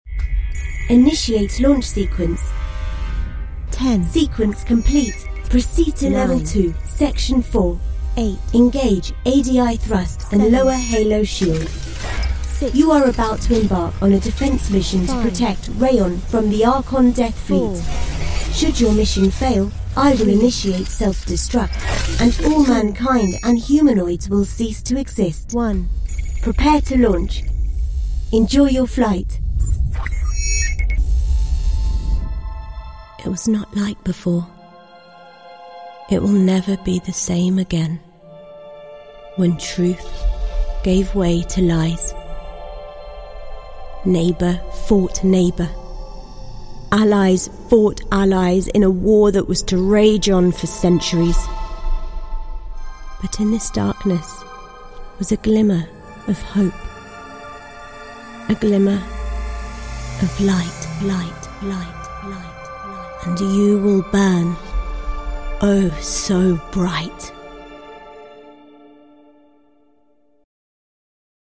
Female / 20s, 30s, 40s / English / Gaming, Indian, Southern
Showreel